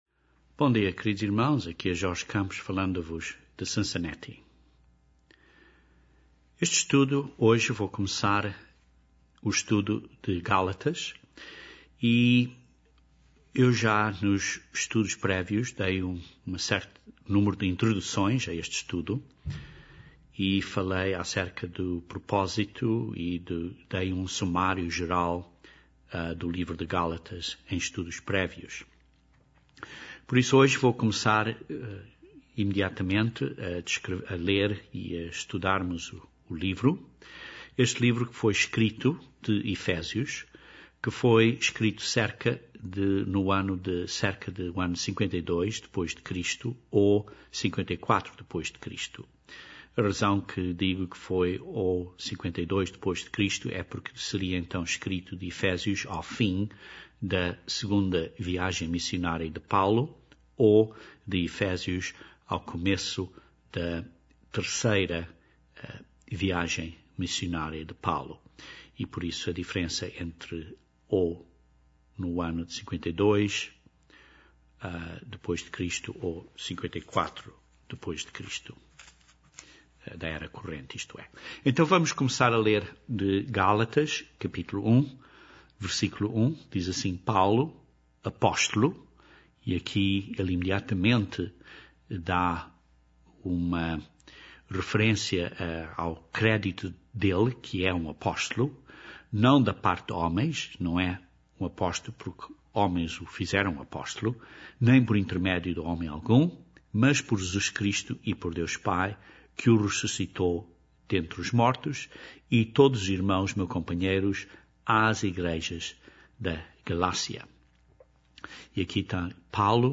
Estudo do primeiro capítulo de Gálatas. Quando ouvir este estudo bíblico, pergunte a se mesmo se sabe?